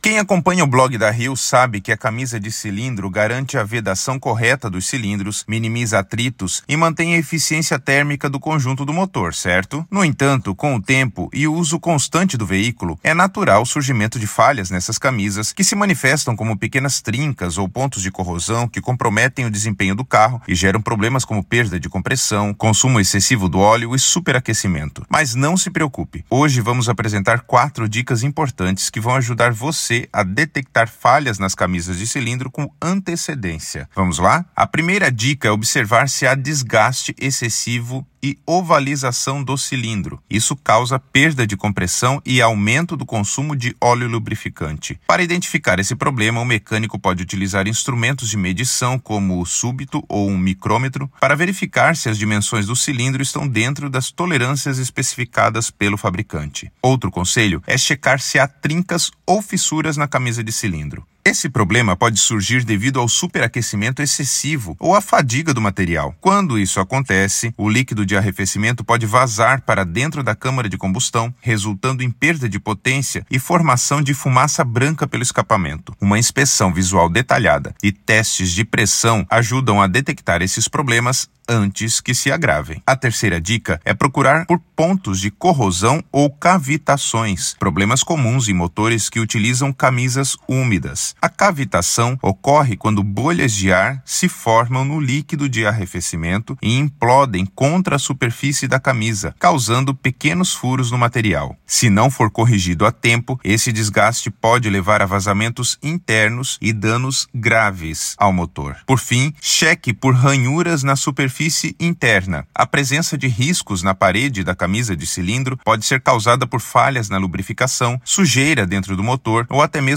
Narracao-03-falhas-nas-camisas-de-cilindro_1.mp3